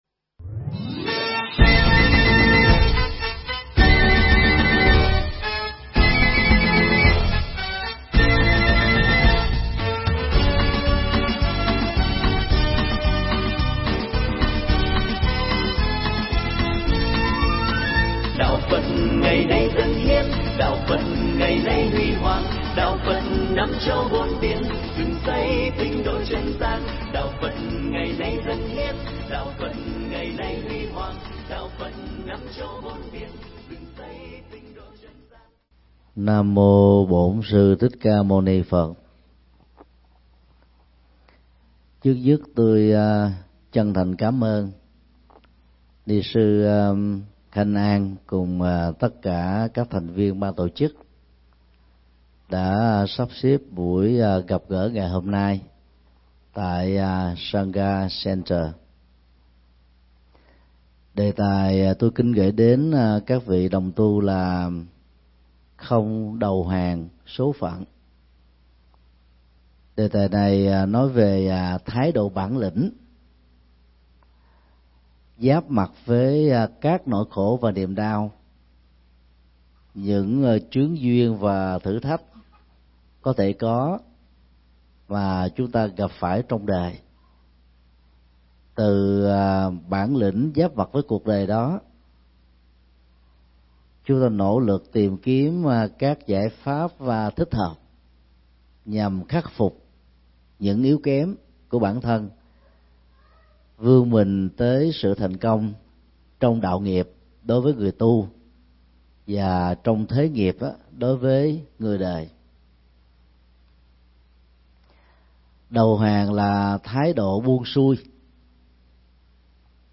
Mp3 Pháp Thoại Không Đầu Hàng Số Phận – Thượng Tọa Thích Nhật Từ giảng tại Sangha Center (Hoa Kỳ), ngày 20 tháng 6 năm 2017